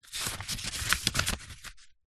Звуки паспорта
Звук проверки документов в паспортном столе